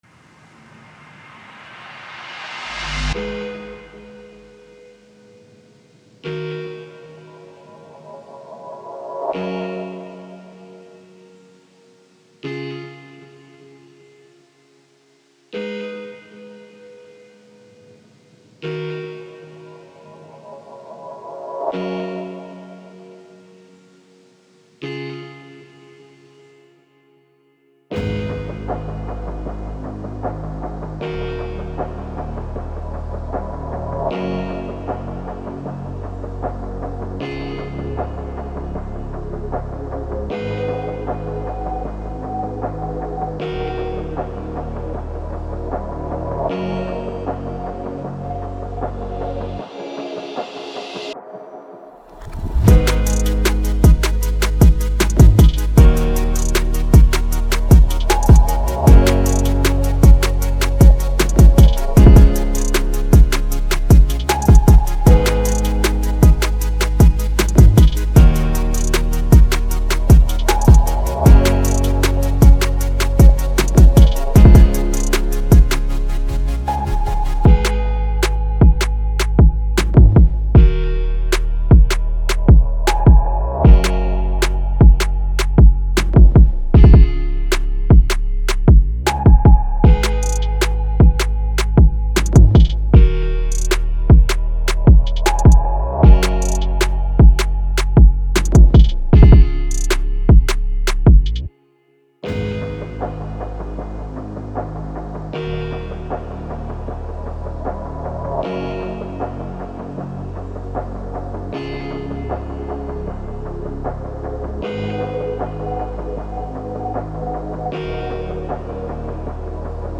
EDM
G# min